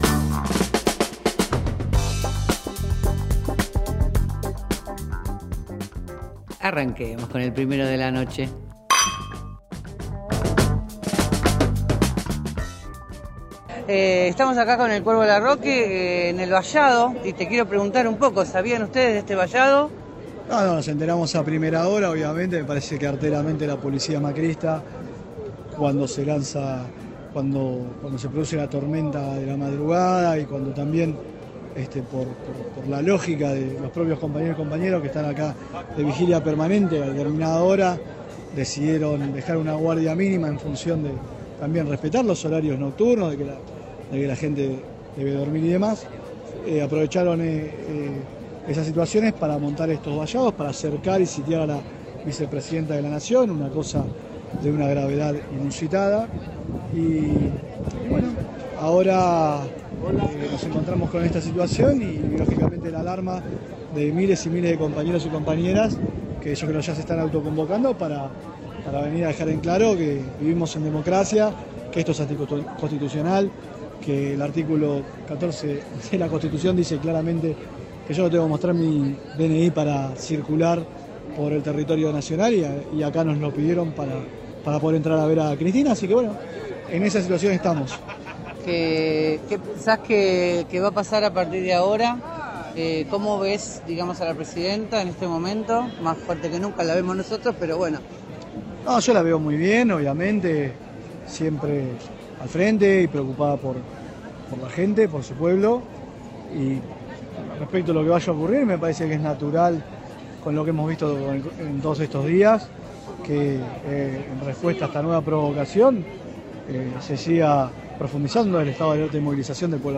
En declaraciones para FM Líder, Andrés «El Cuervo» Larroque, planteó la falta de respeto a los derechos de los ciudadanos que mostró Horacio Rodriguez Larreta al instalar un vallado frente a la casa de Cristina Fernández de Kirchner durante la noche, después de la tormenta.